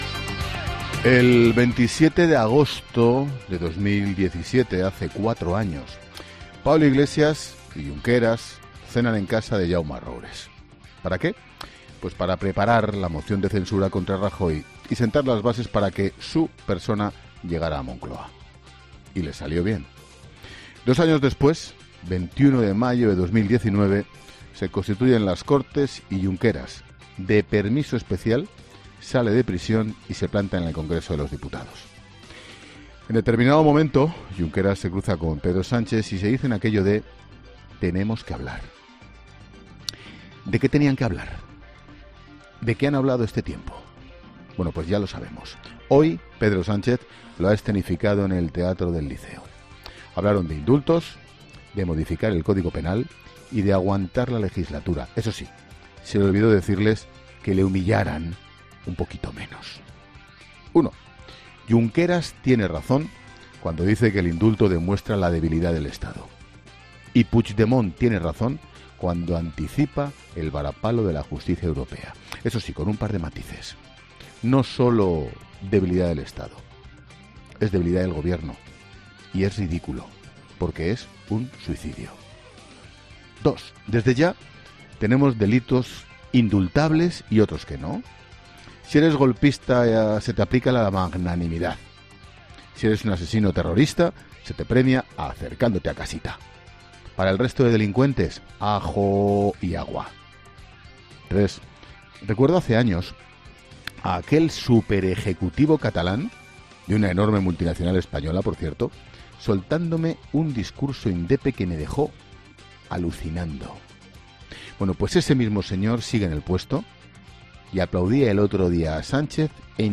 Monólogo de Expósito
El director de 'La Linterna', Ángel Expósito, reflexiona en su monólogo sobre los indultos